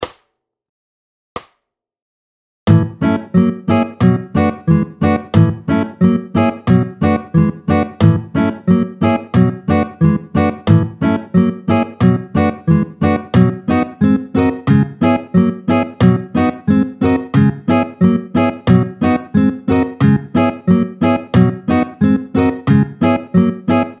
Vite, tempo 90